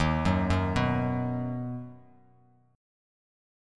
D#m#5 chord